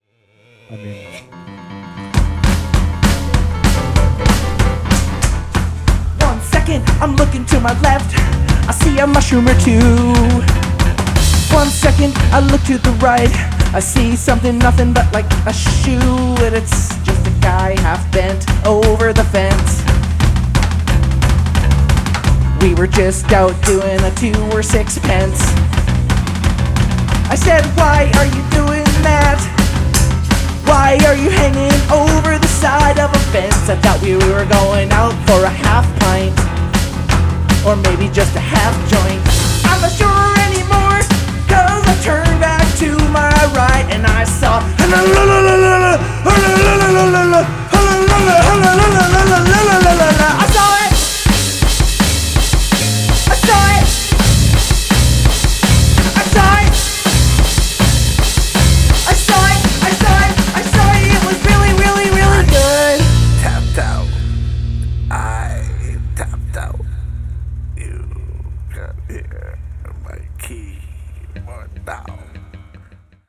Main Vocals
Drums
Piano
Bass Guitar
Backup Vocals
Drum Rock 1m13s Aug 3rd, 2025 (Aug 26th, 2025)